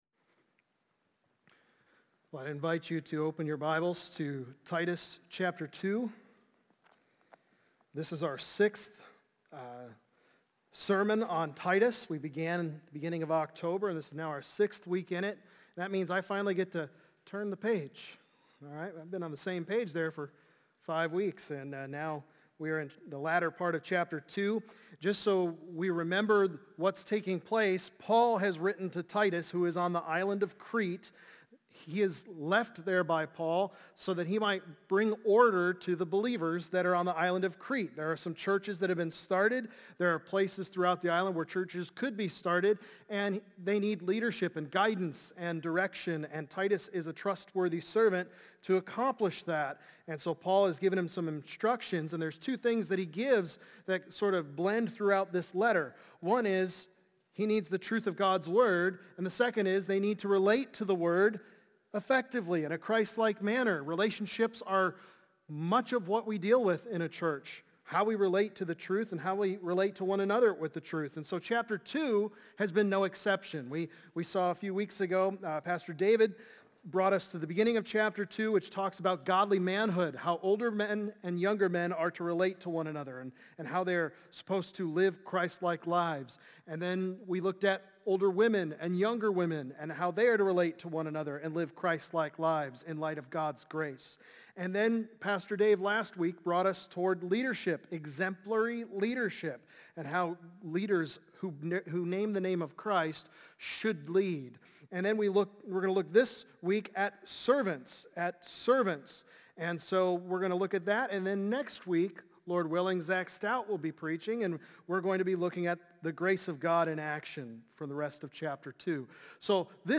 Service That Speaks | Baptist Church in Jamestown, Ohio, dedicated to a spirit of unity, prayer, and spiritual growth